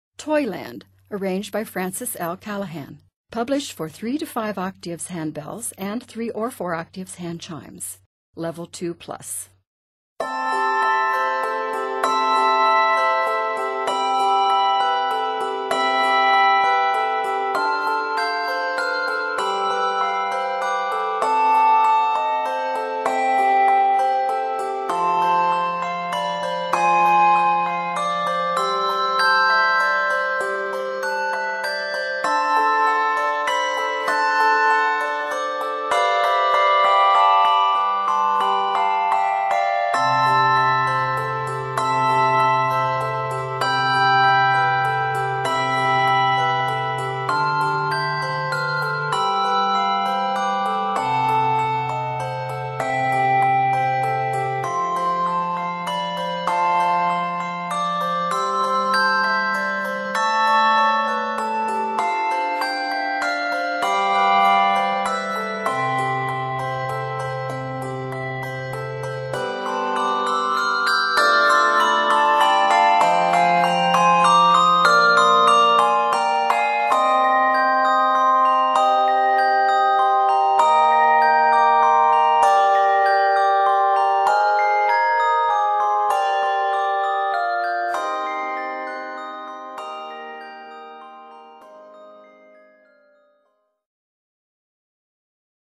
handbell arrangement